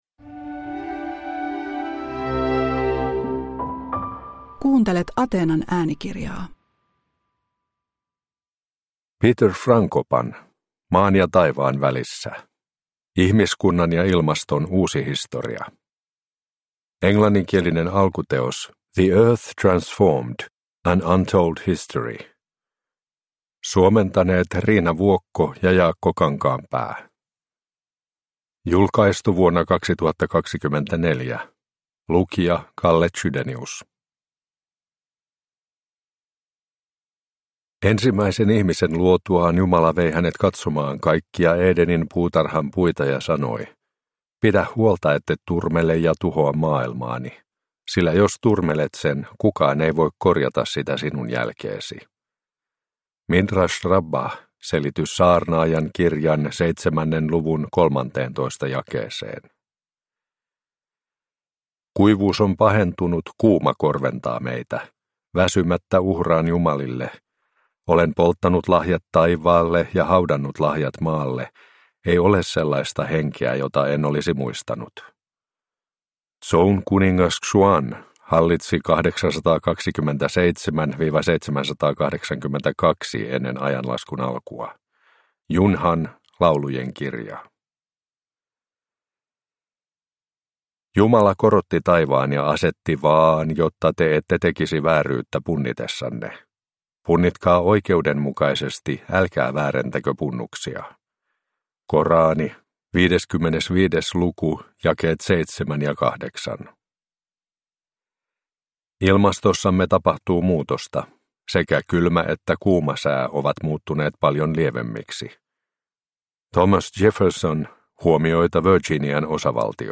Maan ja taivaan välissä – Ljudbok